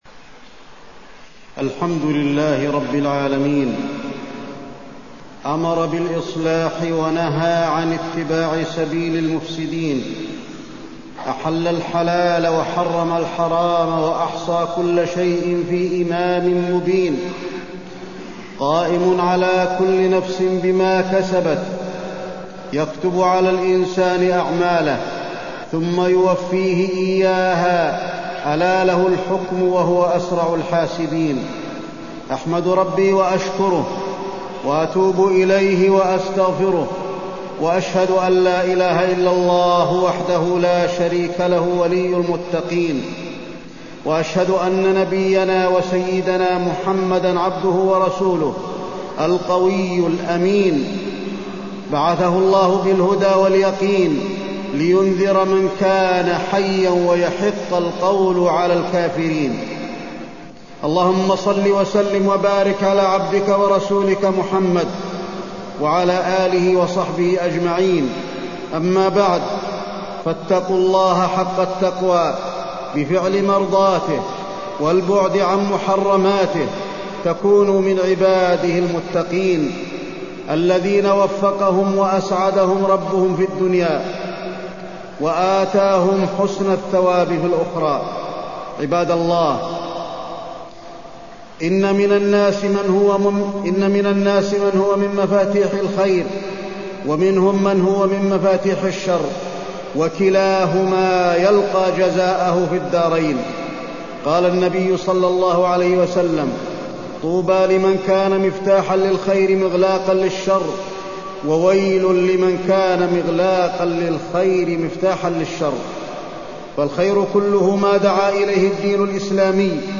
تاريخ النشر ١٥ ربيع الأول ١٤٢٤ هـ المكان: المسجد النبوي الشيخ: فضيلة الشيخ د. علي بن عبدالرحمن الحذيفي فضيلة الشيخ د. علي بن عبدالرحمن الحذيفي الأحداث التي وقعت في الرياض